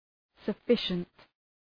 Shkrimi fonetik {sə’fıʃənt}